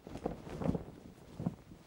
cloth_sail5.L.wav